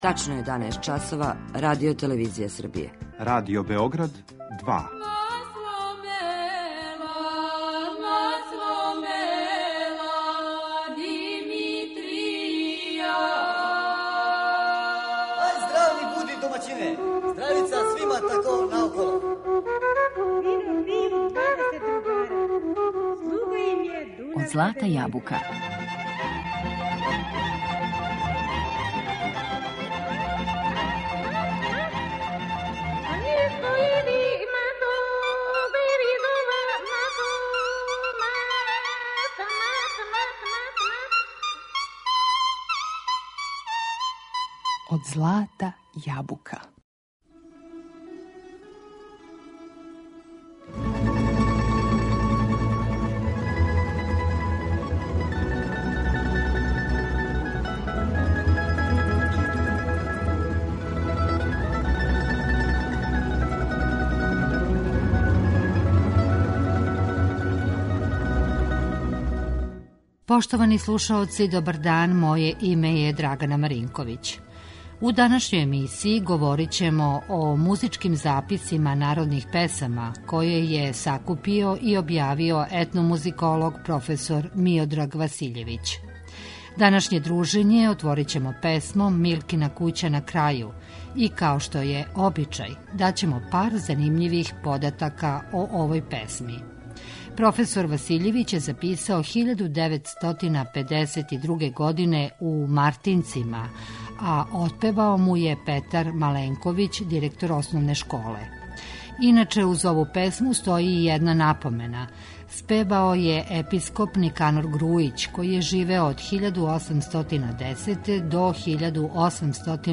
Народне песме из Војводине